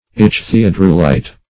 Search Result for " ichthyodorulite" : The Collaborative International Dictionary of English v.0.48: Ichthyodorulite \Ich`thy*o*dor"u*lite\, n. [Gr.